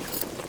tac_gear_26.ogg